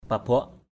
/pa-bʊaʔ/ 1.